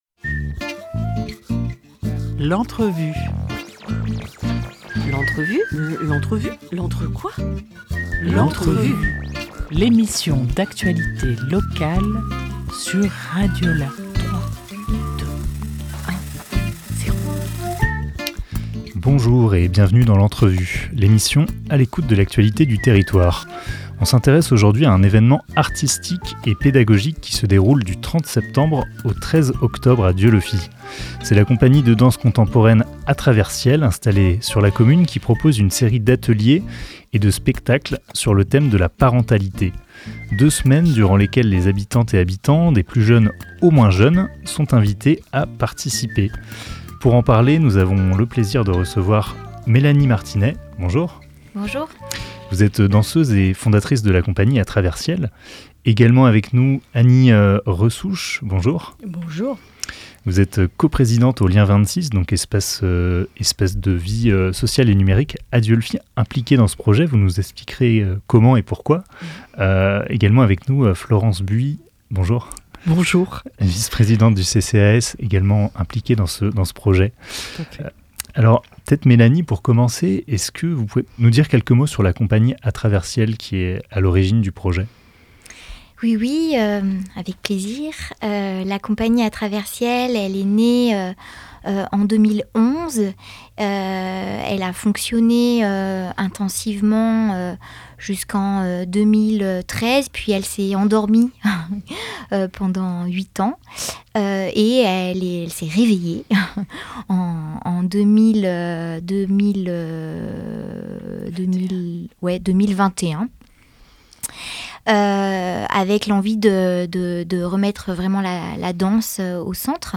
10 septembre 2024 16:07 | Interview